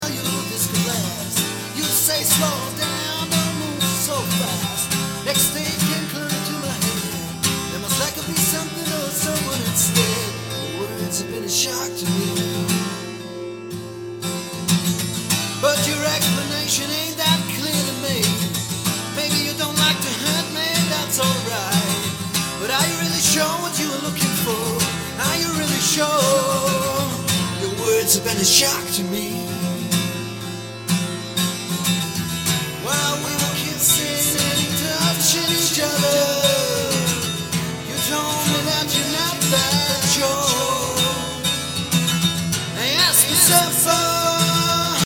Too far out. vocals, acoustic guitar, Telemaster